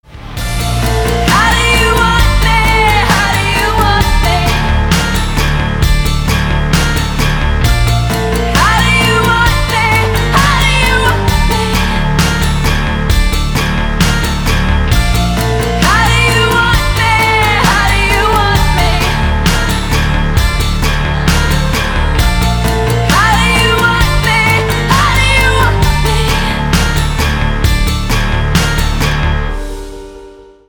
🎶 Рингтоны